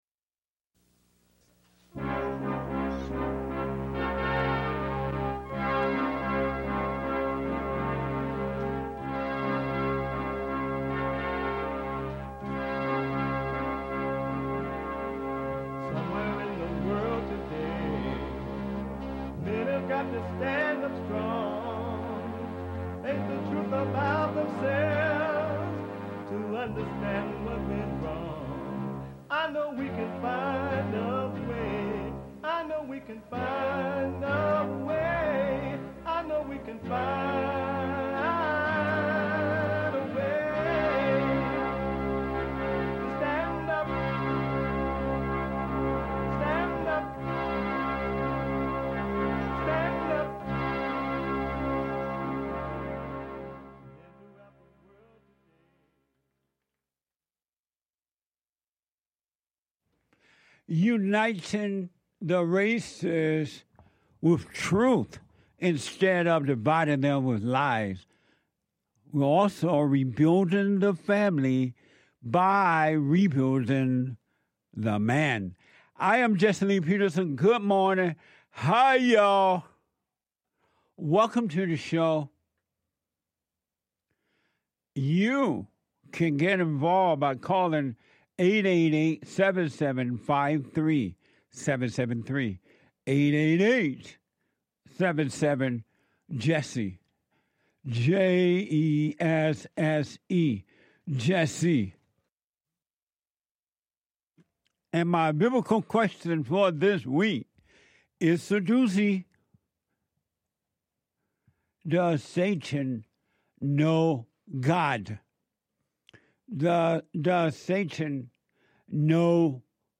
The Jesse Lee Peterson Radio Show